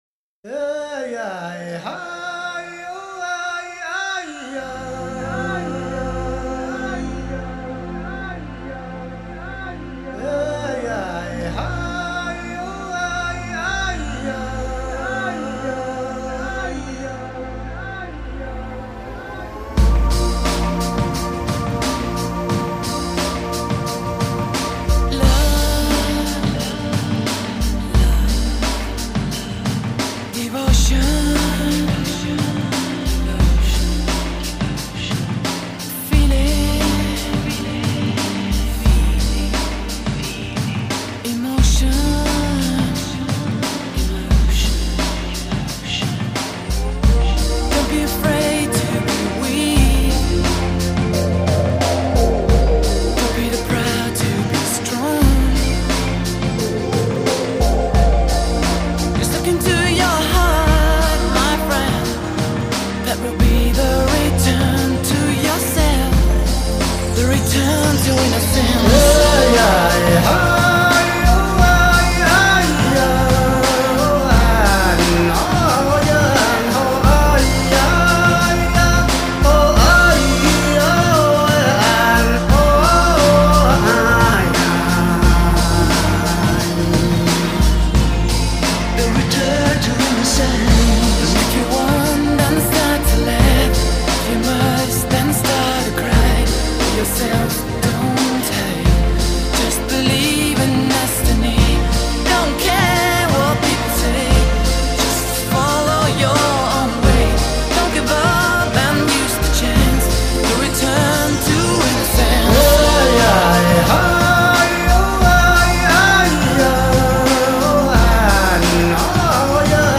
谜一般的音乐，带来令人神往的意境与旋律……